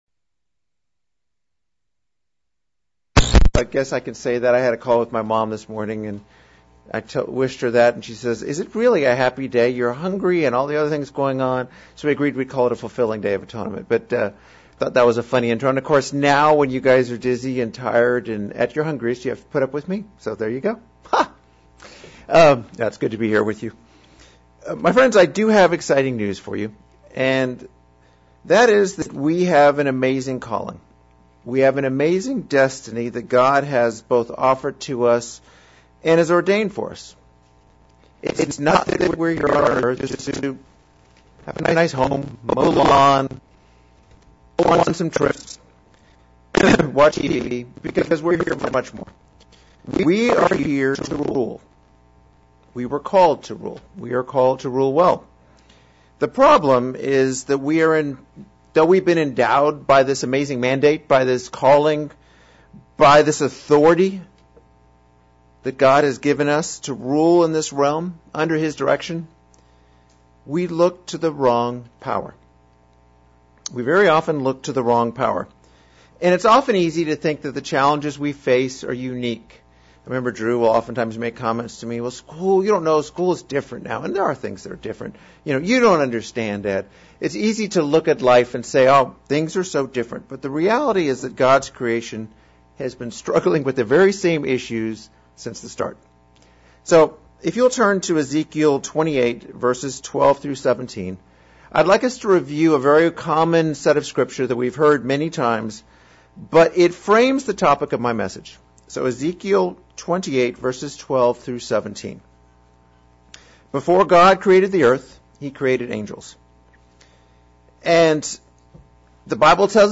Atonement Split Sermon. A look at the uses of Authority vs. Power in the Bible.